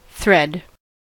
thread: Wikimedia Commons US English Pronunciations
En-us-thread.WAV